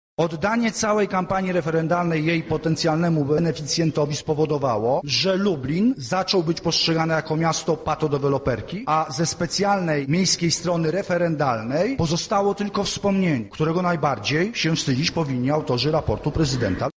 Podczas XX zwyczajnej sesji Rady Miasta mieszkańcy otrzymali możliwość zabrania głosu w dyskusji na temat rozwoju Lublina w ubiegłym roku.